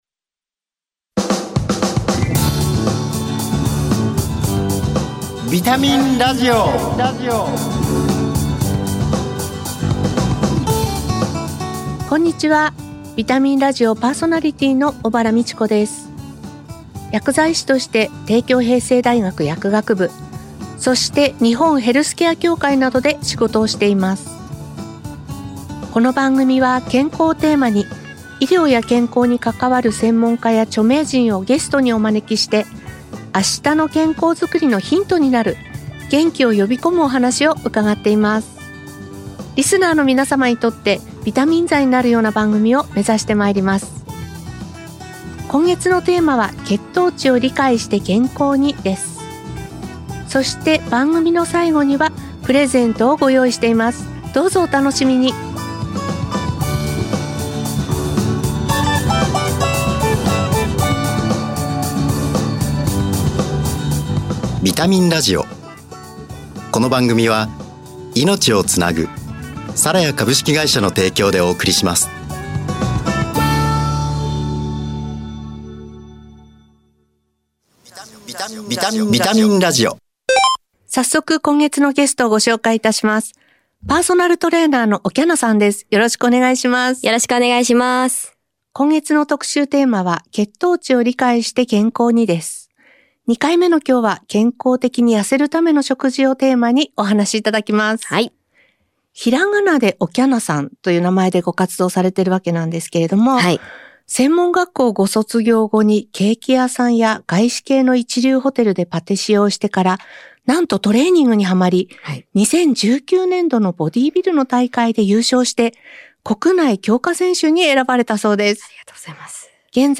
「健康」をテーマに、元気になる情報をお届けします。医療従事者など専門家がゲストに登場。